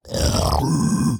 Minecraft Version Minecraft Version snapshot Latest Release | Latest Snapshot snapshot / assets / minecraft / sounds / mob / piglin_brute / angry1.ogg Compare With Compare With Latest Release | Latest Snapshot
angry1.ogg